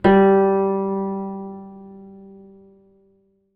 ZITHER G 1.wav